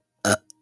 Burp / Belch
belch belching boy burp burping disgusting funny gross sound effect free sound royalty free Funny